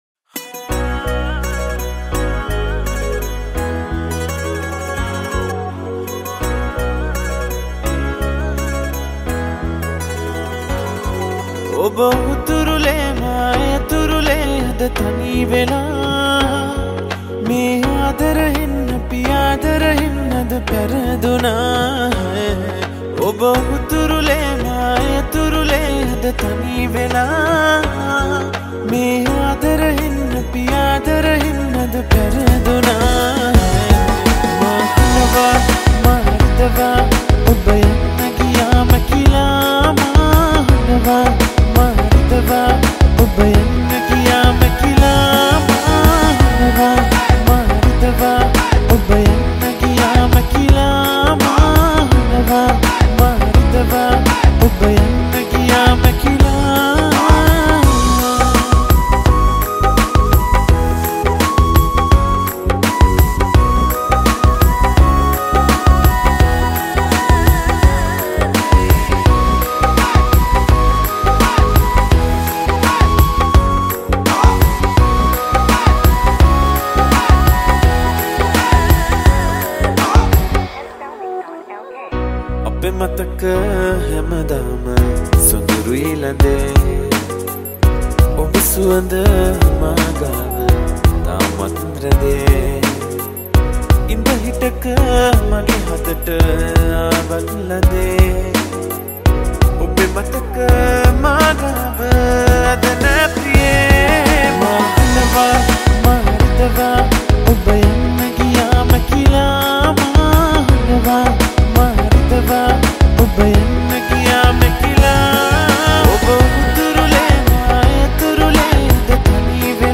Sinhala Version